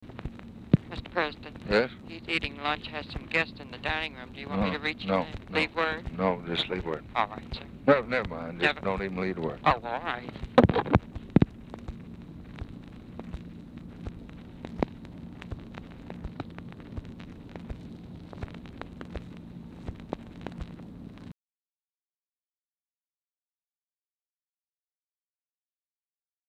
Format Dictation belt
Location Of Speaker 1 Oval Office or unknown location
Speaker 2 TELEPHONE OPERATOR Specific Item Type Telephone conversation